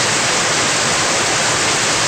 Storm1.ogg